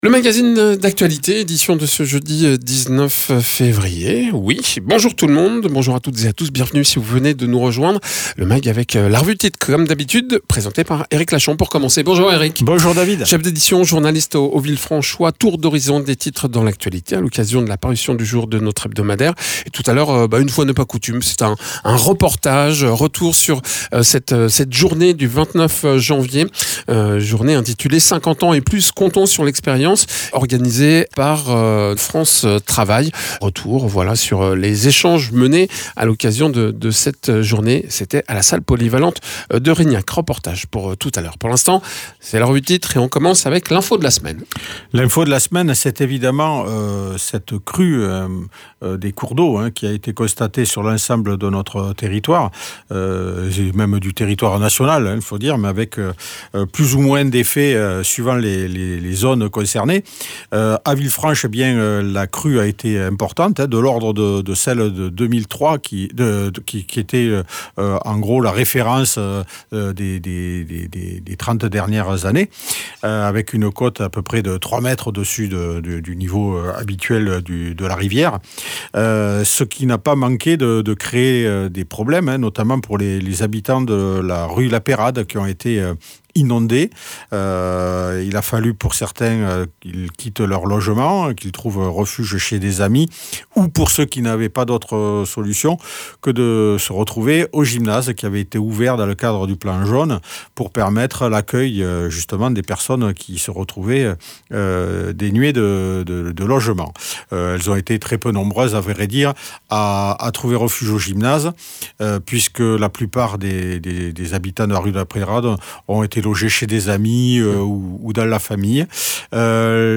Avec, les crues très importantes, inondations et évacuations, une mobilisation pour défendre des postes d’enseignants, présentation de liste municipale, lumière sur la grotte de Foissac, maintient de l’accueil périscolaire au domaine de Laurière, ainsi que les sorties du week-end. Egalement dans ce mag, reportage sur une manifestation intitulée 50 ans et plus comptons sur l’expérience, organisée par France Travail en collaboration avec les partenaires du Réseau pour l’Emploi.